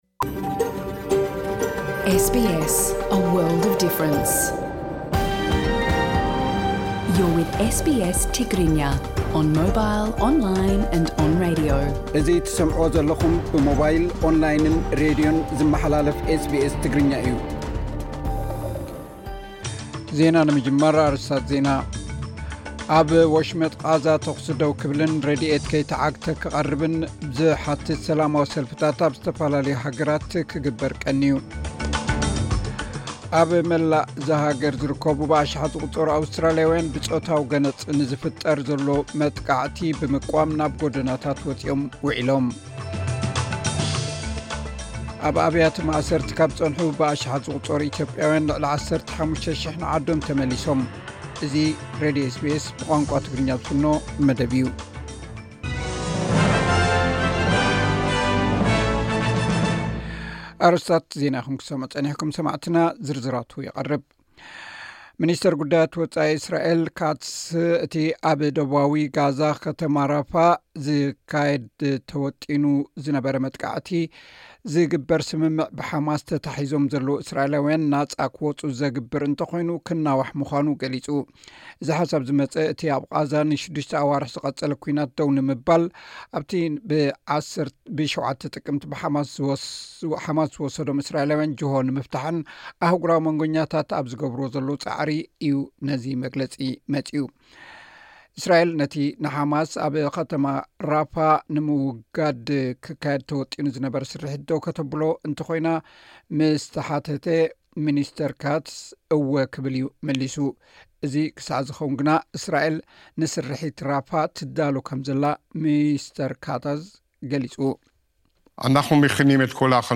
ዜናታት ኤስ ቢ ኤስ ትግርኛ (29 ሚያዝያ 2024)